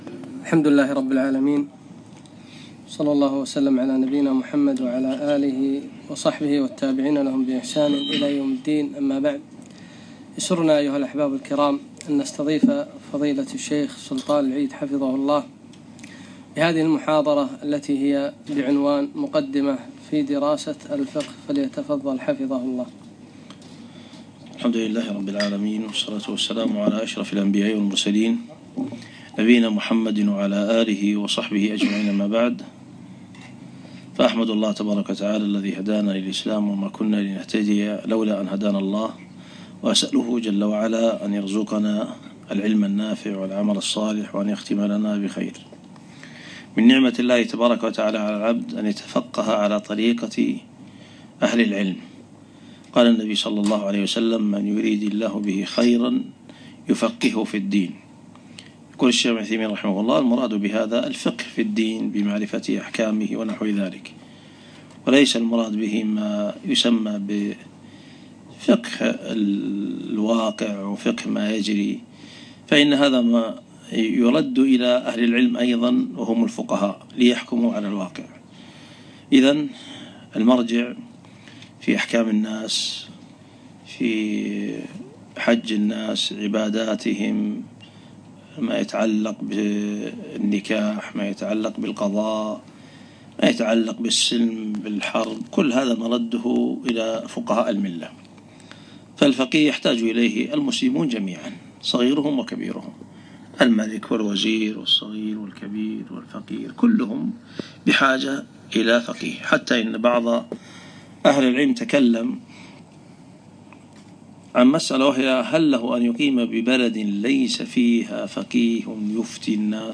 يوم الجمعة 8 ربيع الثاني 1438 الموافق 6 1 2017 في مخيم شباب ابن تيمية الجهراء